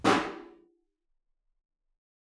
timp-snare_f.wav